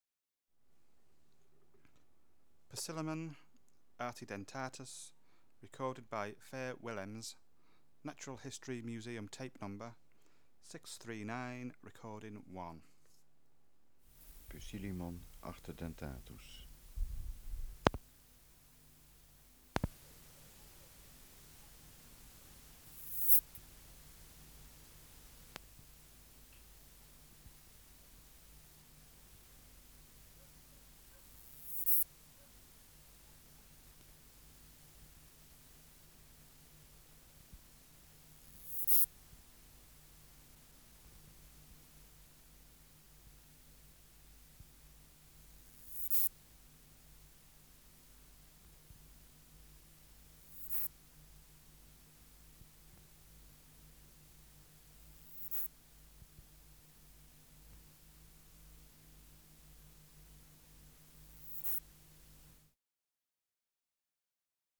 Air Movement: Nil Substrate/Cage: In cage Biotic Factors / Experimental Conditions: Isolated male
Microphone & Power Supply: AKG D202 (LF circuit off))